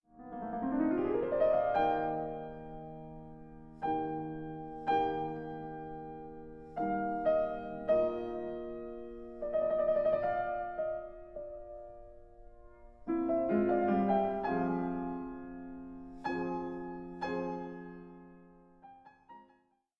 15 Variations and a Fugue in E Flat Major, Op. 35